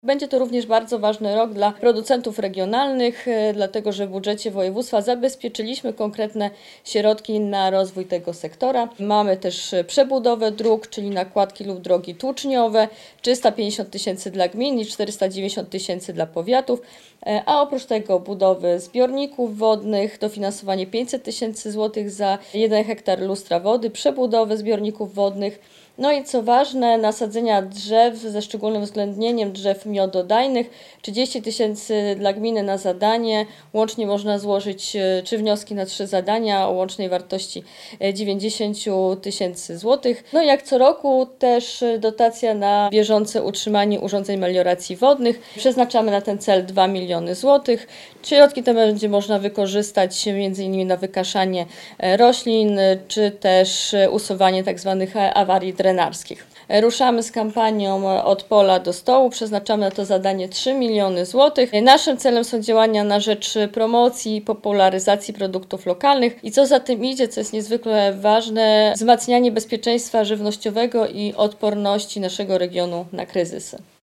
Podczas konferencji prezentującej założenia budżetowe członkowie zarządu województwa podkreślili, że przyszłoroczny budżet będzie większy od tegorocznego o 800 mln zł.
W roku 2026 utrzymujemy dotychczasowe konkursy i programy, ale też zwiększamy środki wsparcia na kluczową infrastrukturę w dolnośląskich wsiach – mówi Natalia Gołąb, członek zarządu Województwa Dolnośląskiego.